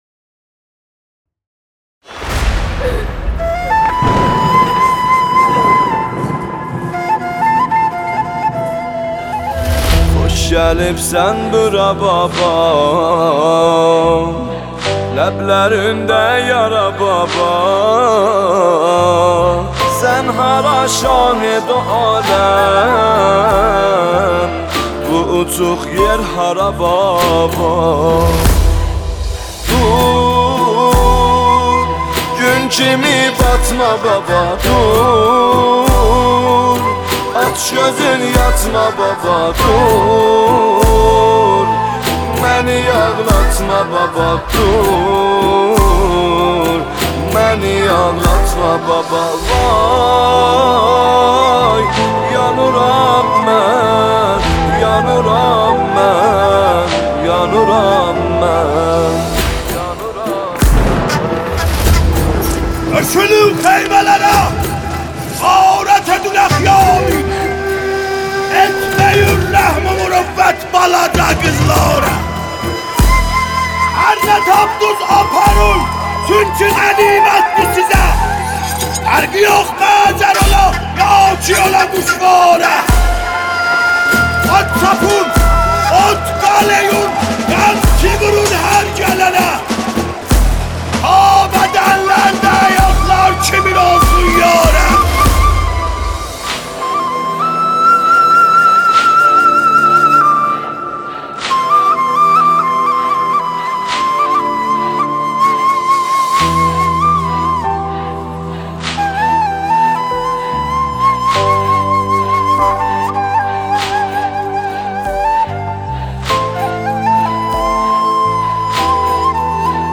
دانلود مداحی ترکی